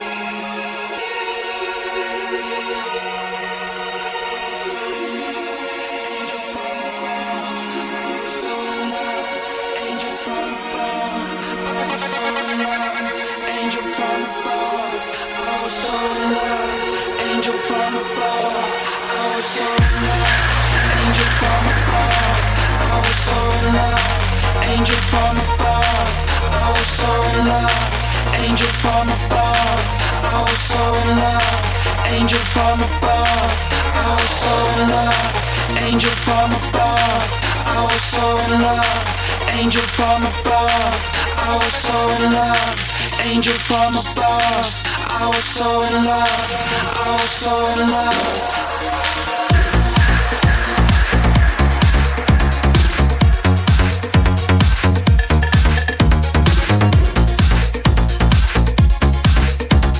Электронная
Шестой выпуск сборника клубной мgузыки Екатеринбурга.